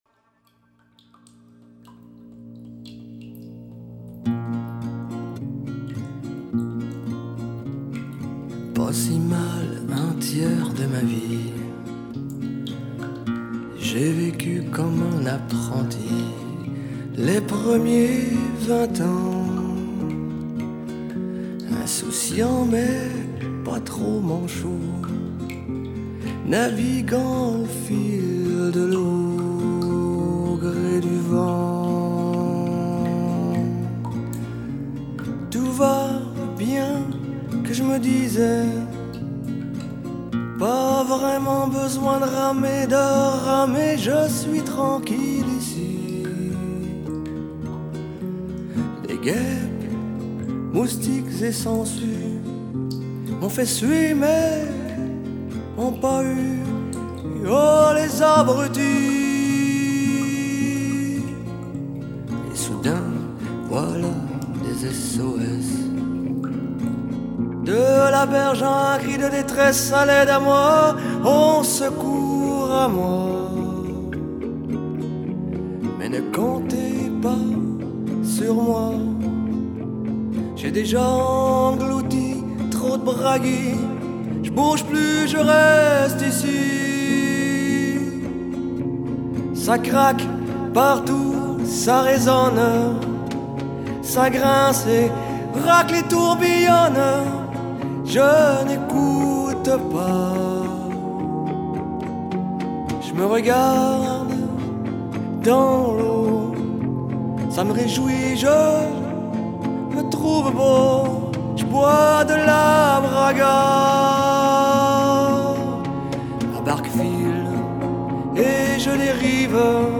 контрабас
аккордеон и скрипка
фортепиано